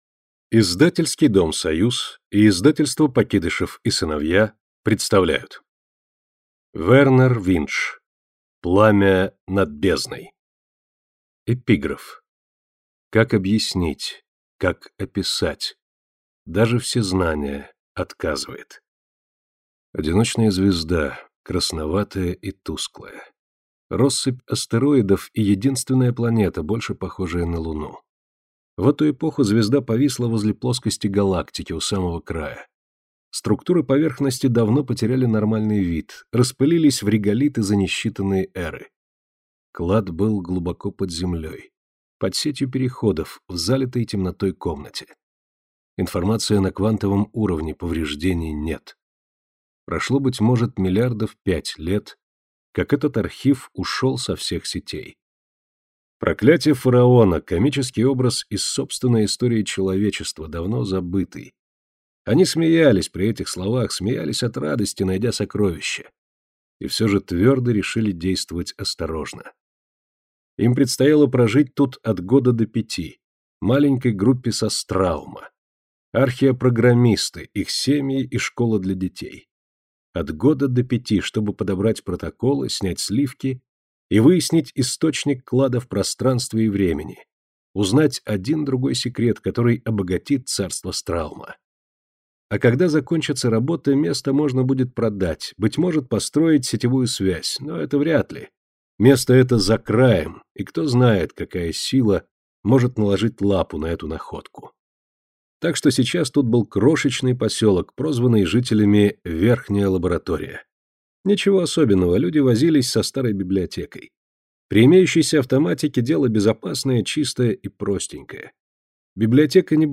Аудиокнига Пламя над бездной | Библиотека аудиокниг